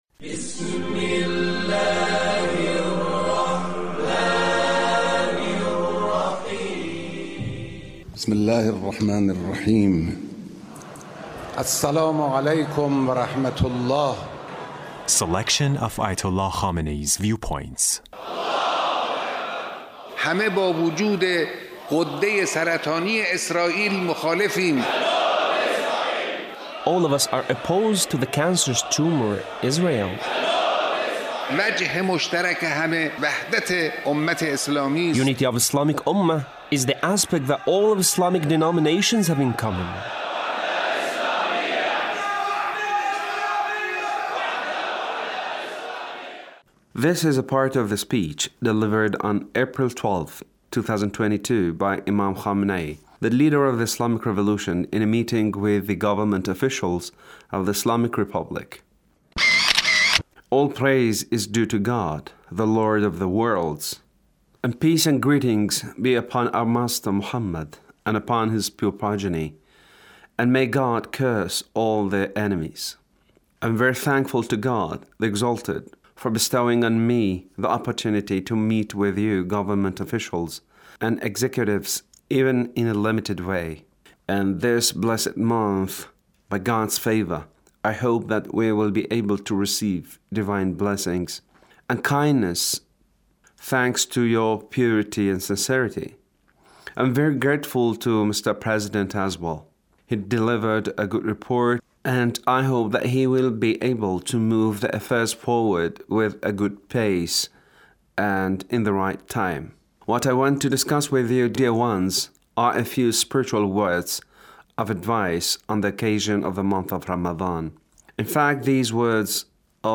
The Leader's speech on Ramadhan